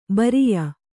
♪ bariya